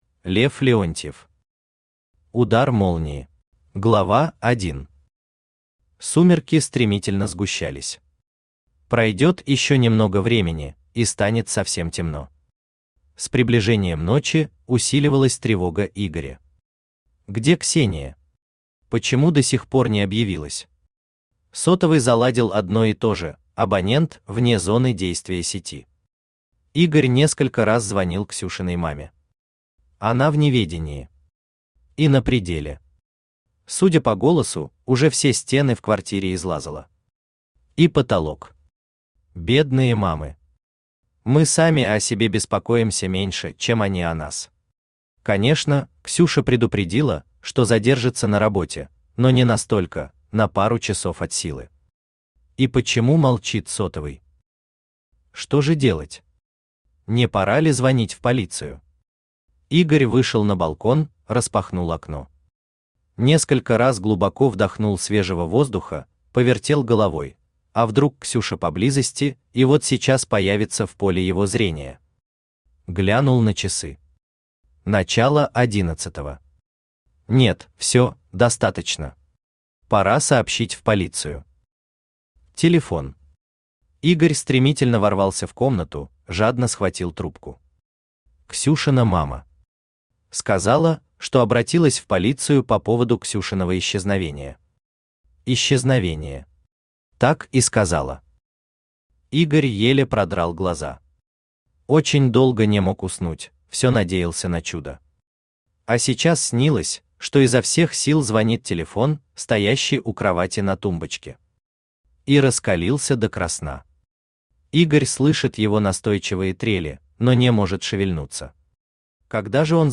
Аудиокнига Удар молнии | Библиотека аудиокниг
Aудиокнига Удар молнии Автор Лев Николаевич Леонтьев Читает аудиокнигу Авточтец ЛитРес.